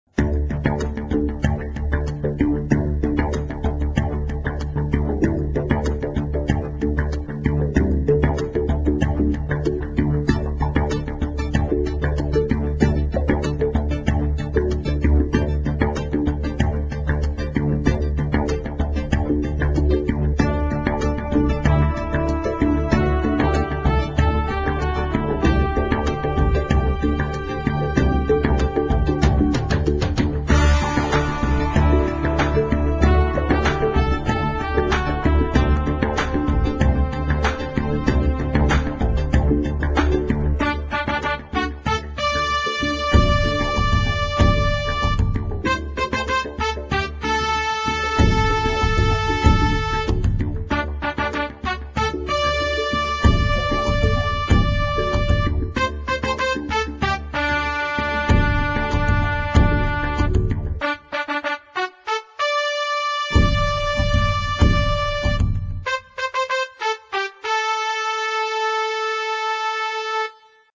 Lutter sangbare og ørehængende slagere finder man her!